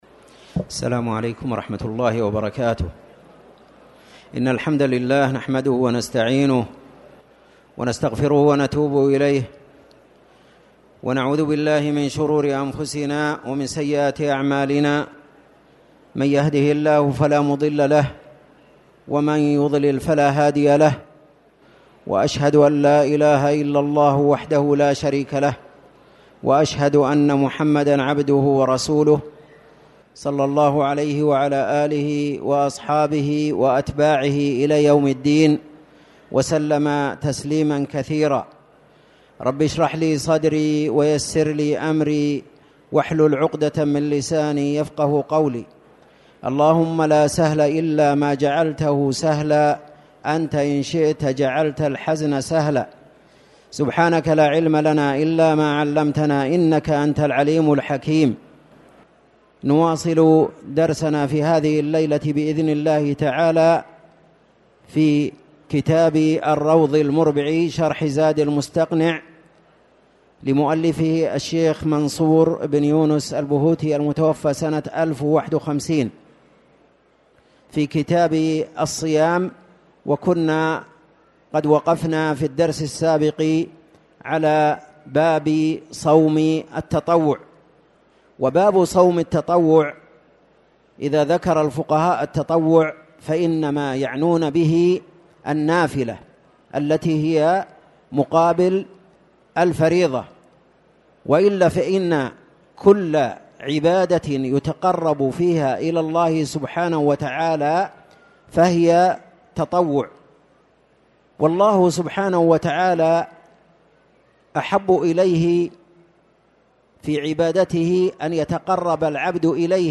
تاريخ النشر ٢ جمادى الأولى ١٤٣٨ هـ المكان: المسجد الحرام الشيخ